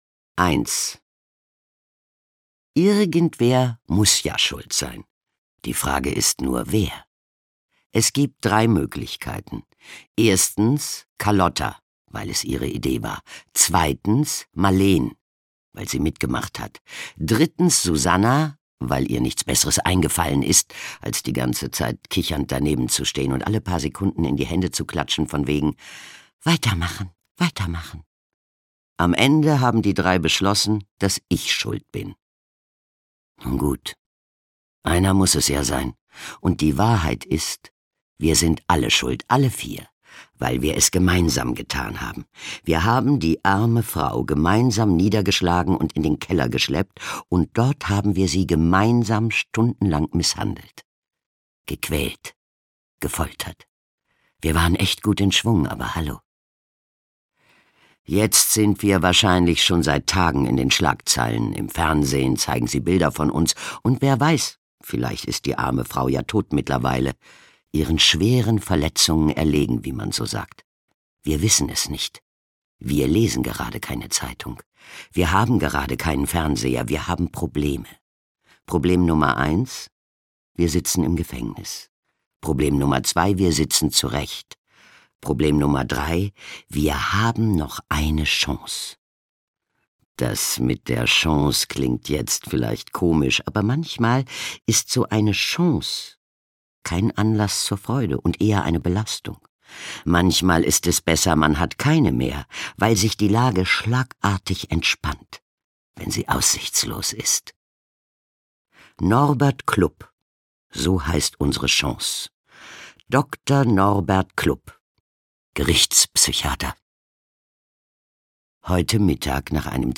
Der Zwerg reinigt den Kittel - Anita Augustin - Hörbuch